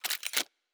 pgs/Assets/Audio/Sci-Fi Sounds/Weapons/Weapon 02 Reload 2.wav at master
Weapon 02 Reload 2.wav